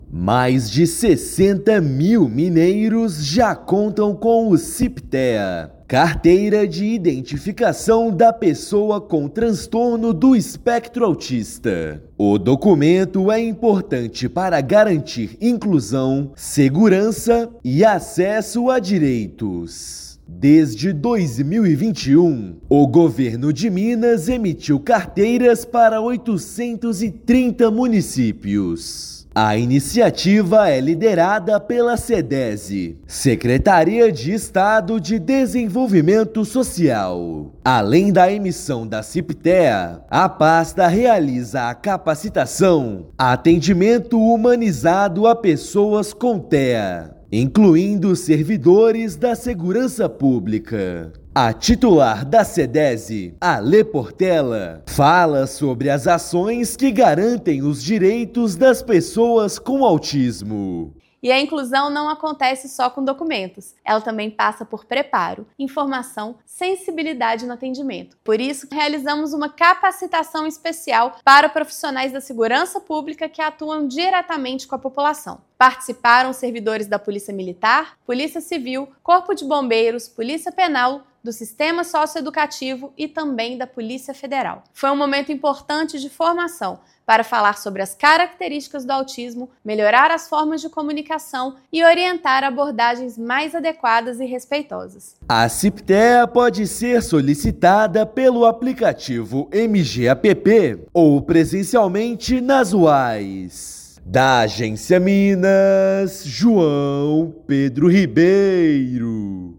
Carteira emitida pela Sedese está presente em 830 municípios; Estado também promove capacitação de Forças de Segurança para abordagem humanizada a pessoas com TEA. Ouça matéria de rádio.